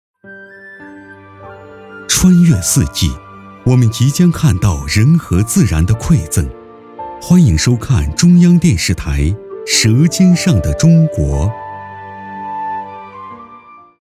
舌尖-男54-开场白.mp3